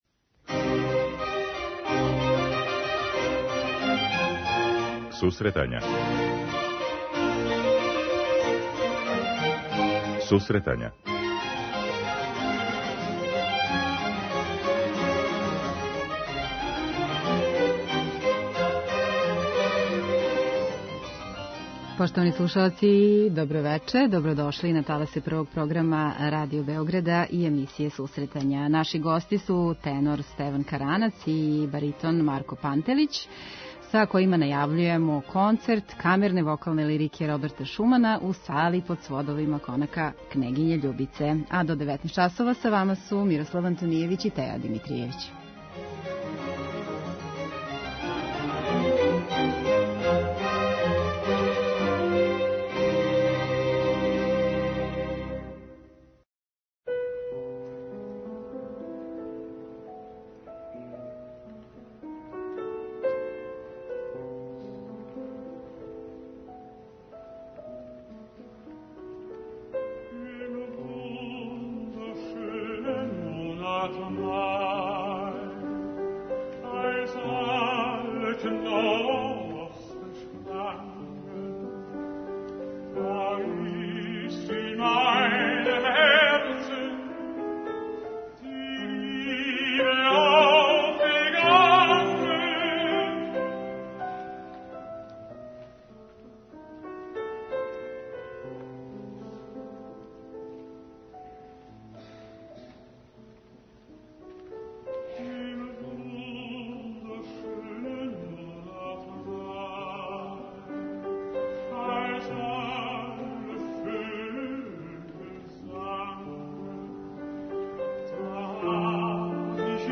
Музичка редакција Емисија за оне који воле уметничку музику.